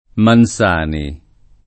[ man S# ni ]